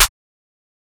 {Snare} Get Right.wav